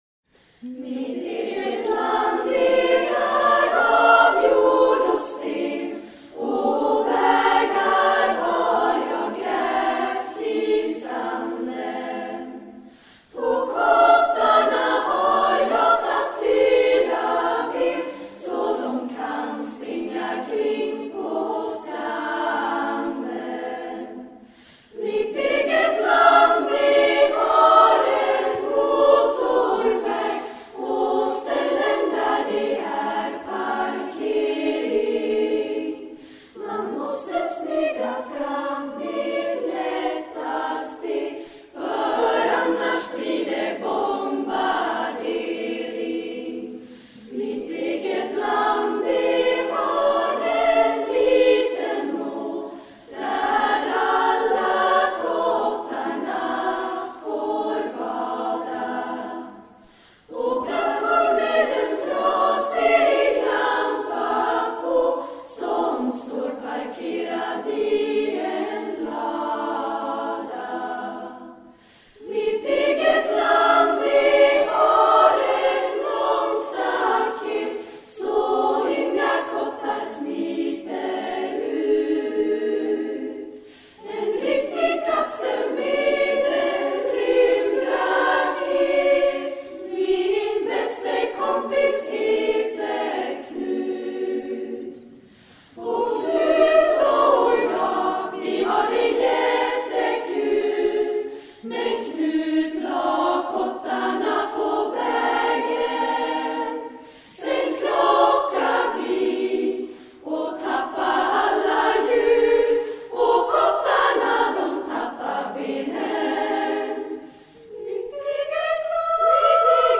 Sånger för barn- och damkör
För barnkör:
SSA tre sidor.